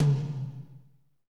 TOM A C H17L.wav